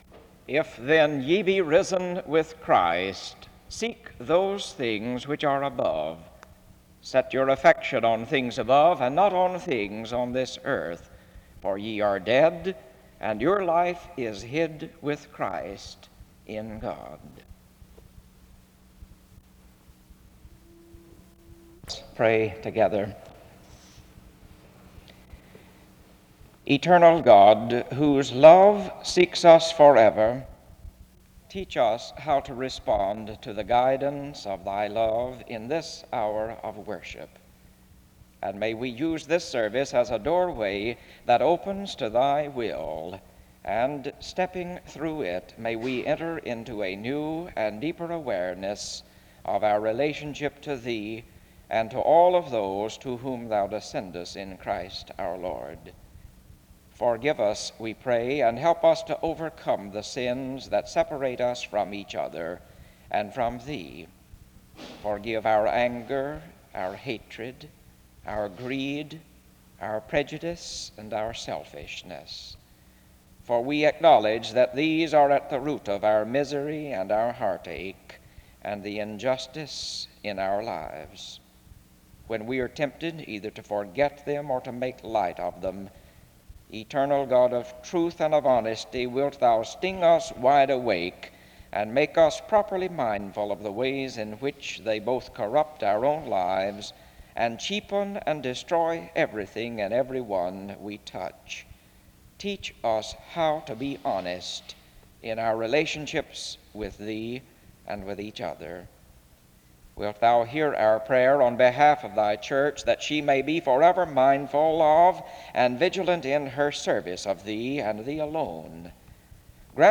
The service begins with prayer from 0:00-3:27.
Music is played from 5:22-8:32.
He notes that without missions the church will be dying. Music plays again from 36:34-40:22. Closing remarks are made from 40:23-40:35. Closing music is played from 40:36-41:36.